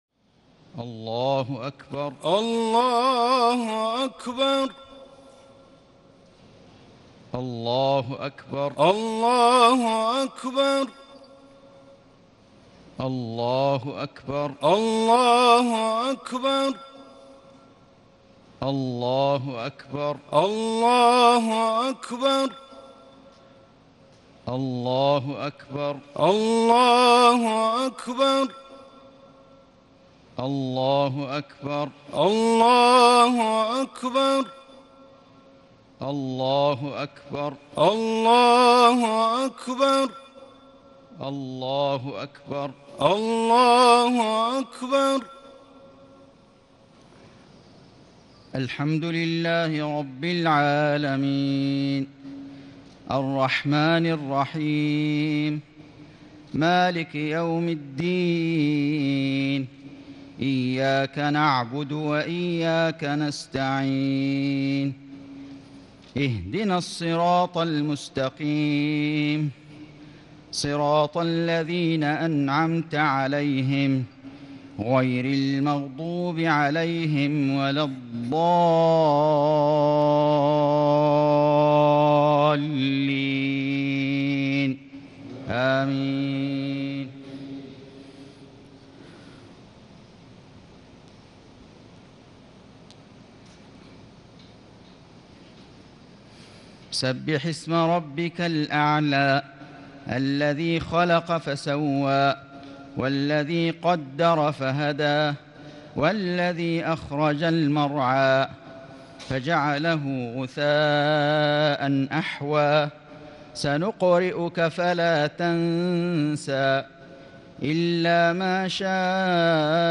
صلاة الاستسقاء 26 جمادى الأولى 1439هـ سورتي الأعلى و الغاشية salat alaistisqa Surah Al-A,laa and Al-Ghaashiya > 1439 🕋 > الفروض - تلاوات الحرمين